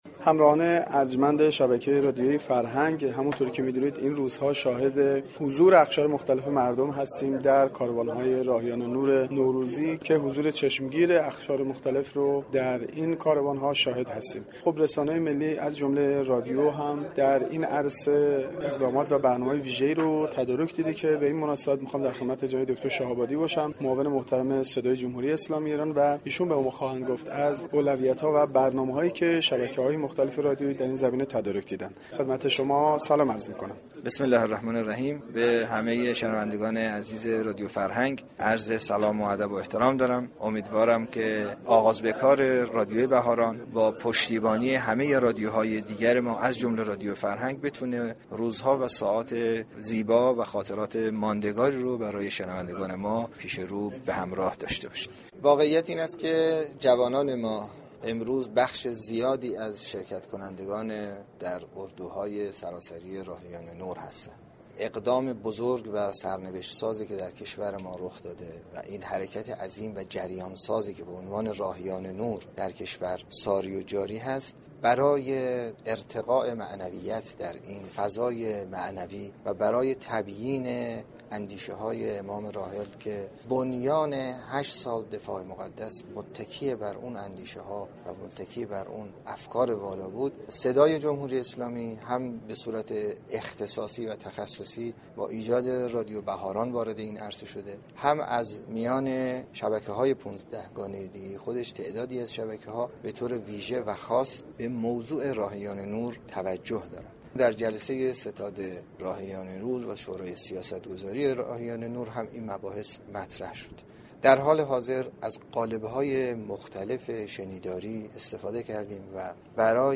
دكتر حمید شاه آبادی معاون محترم صدای رسانه ی ملی در گفتگوی اختصاصی با گزارشگر رادیو فرهنگ با اشاره به آغاز به كار رادیو بهاران گفت : امیدواریم آغاز به كار رادیو بهاران با پشتیبانی شبكه های رادیویی همراه باشد و خاطرات ماندگاری را برای شنوندگان ما به همراه داشته باشد .